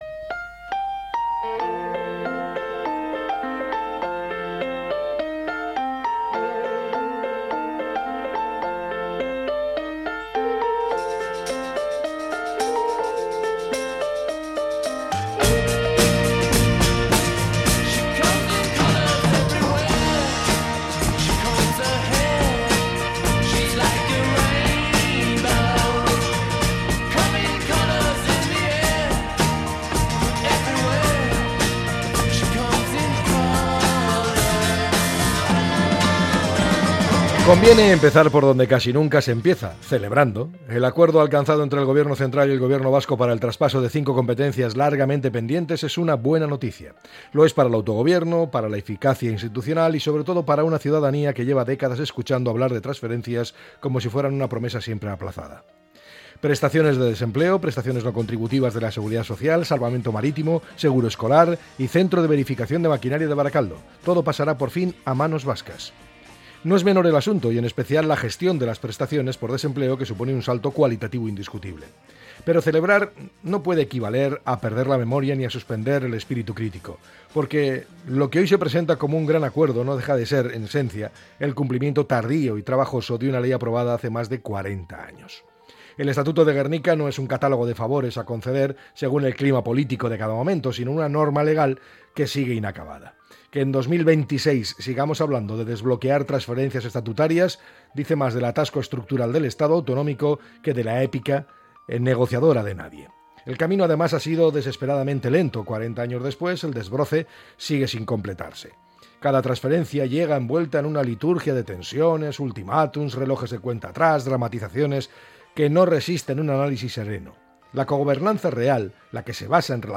El comentario